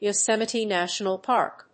音節Yo・sém・i・te Nátional Párk 発音記号・読み方
/joʊséməṭi‐(米国英語), jəʊséməṭi‐(英国英語)/